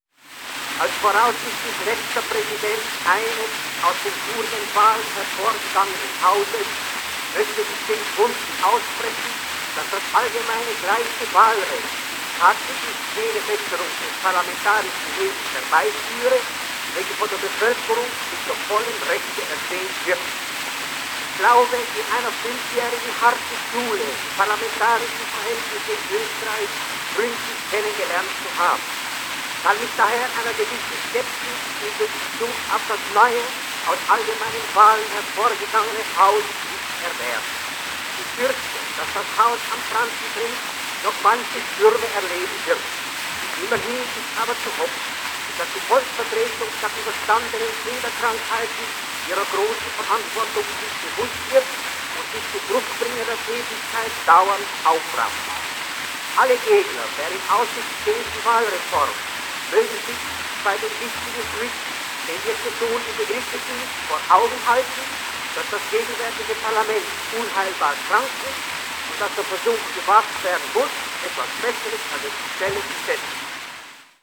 Speech by Moritz Graf Vetter von der Lilie on the introduction of common, equal and direct voting rights in Austria